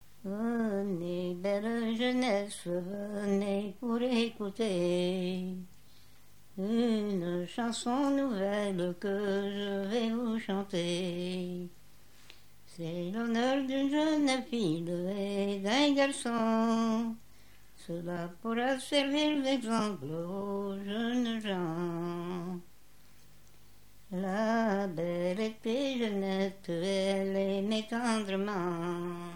Genre strophique
Chansons traditionnelles et populaires
Pièce musicale inédite